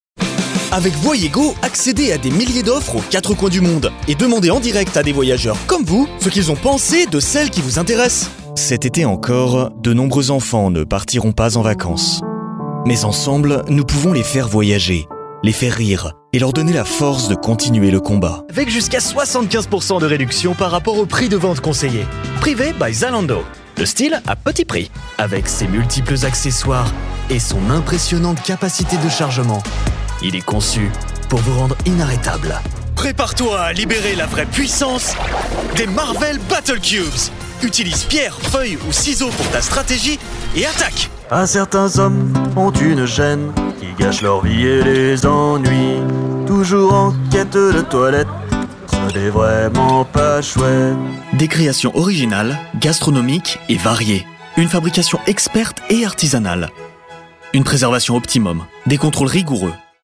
Francês
Esquentar
Confiável
Amigáveis